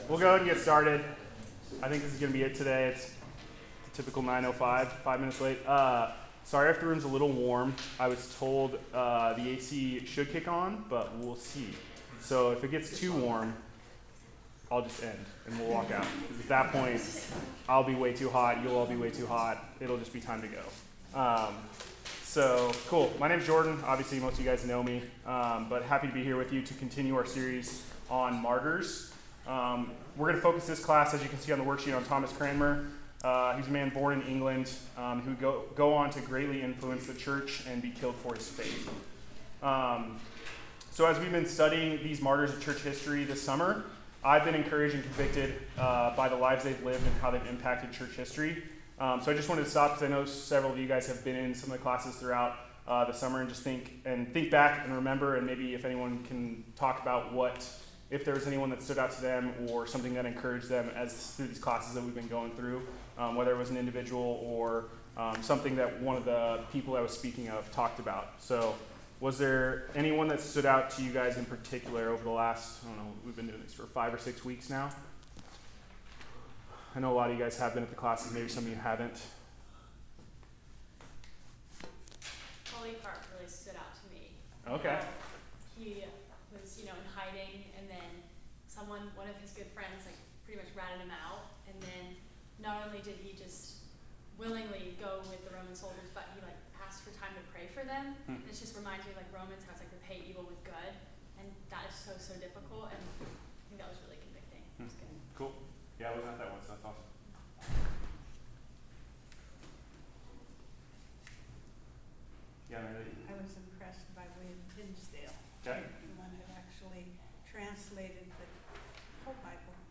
A message from the series "Blood of the Martyrs."